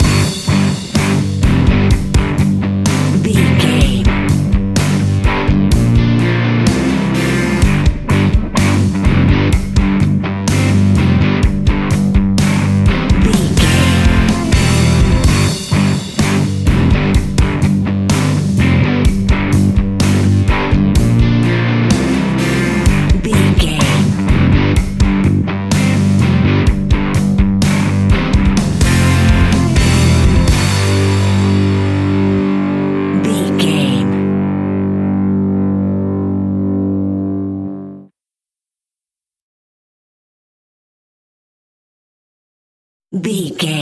Ionian/Major
energetic
driving
happy
bright
electric guitar
bass guitar
drums
hard rock
blues rock
distortion
instrumentals
heavy drums
distorted guitars
hammond organ